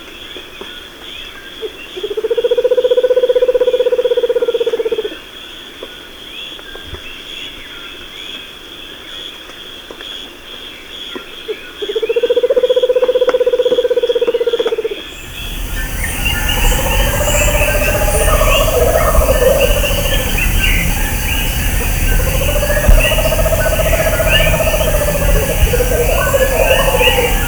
Puerto Rican Screech-Owl
VOZ: Un trino grave y callado. De cerca se puede escuchar un chasquido justo al empezar del canto.